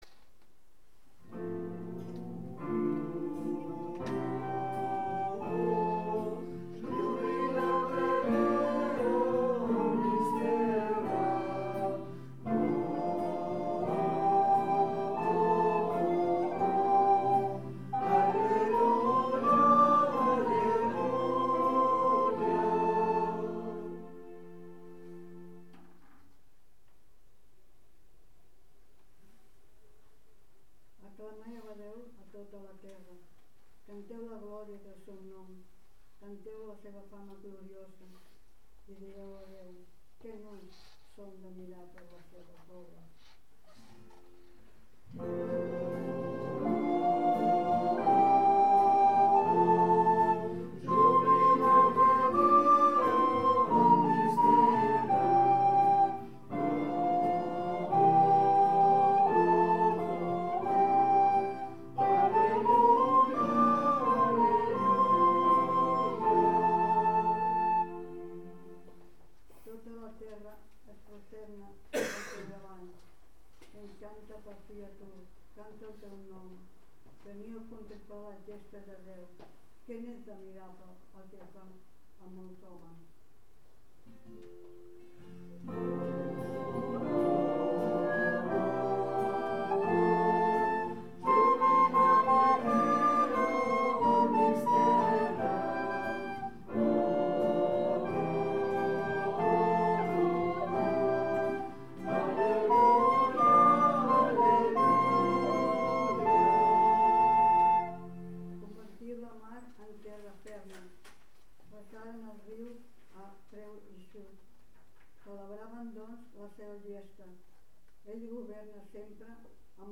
Pregària de Taizé
Capella dels Salesians - Diumenge 25 de maig de 2014